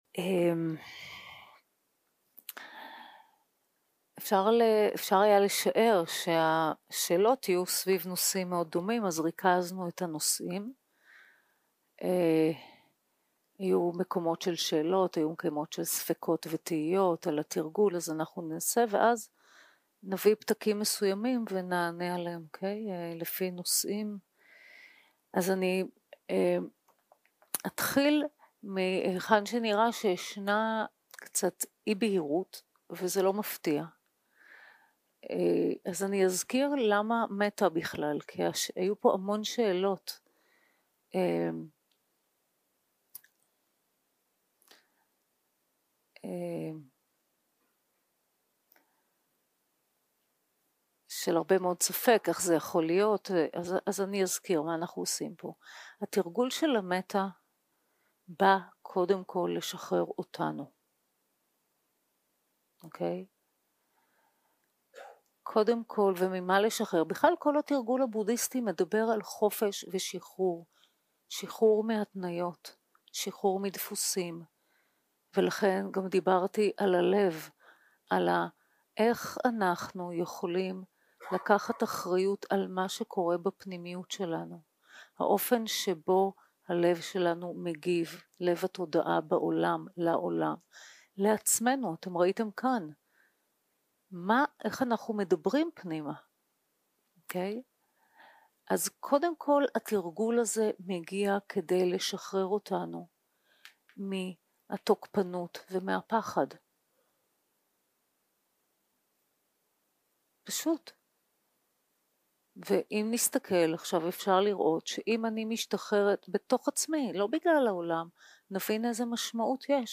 סוג ההקלטה: שאלות ותשובות